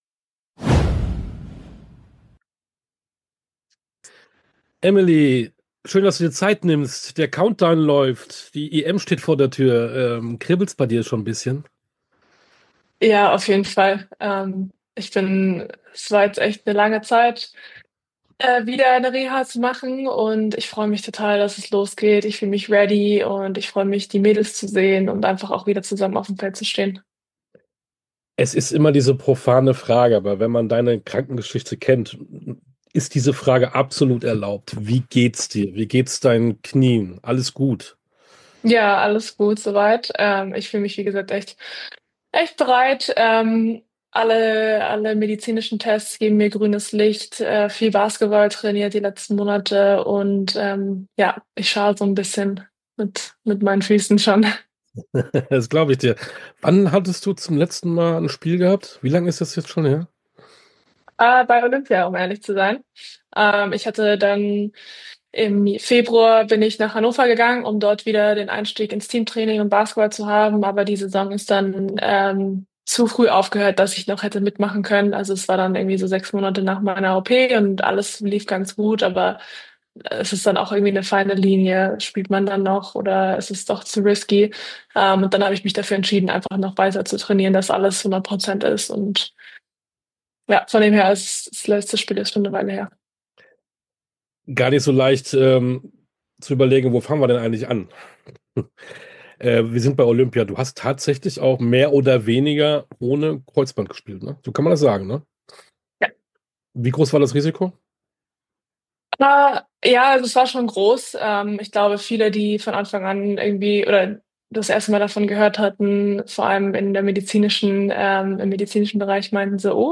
Sportstunde - Interview komplett Emily Bessoir, Basketball Nationalspielerin ~ Sportstunde - Interviews in voller Länge Podcast
Tauchen Sie ab in die Sportstunde ungeungeschnittentinterviews in ihrer authentischen, ungeschnittenen Langfassung.
Interview_Emily_Bessoir-_Basketball_-_Nationalspielerin_(1).mp3